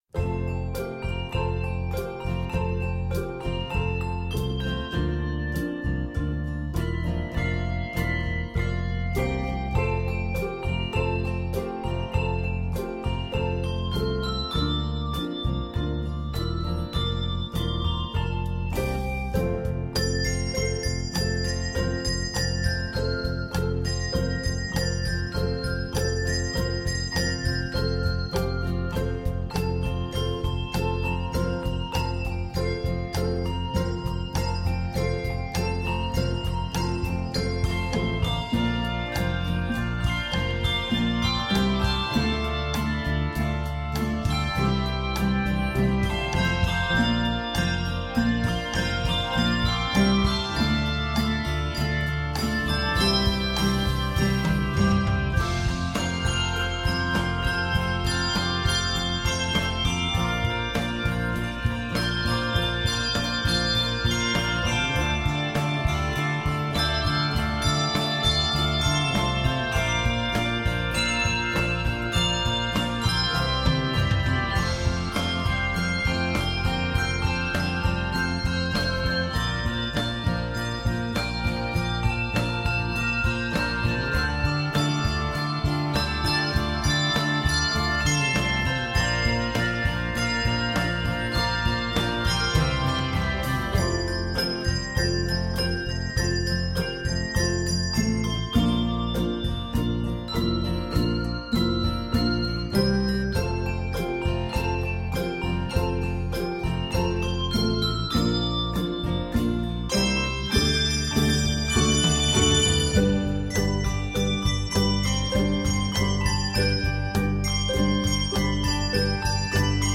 Rhythmic drive and energy prevail throughout
contemporary praise song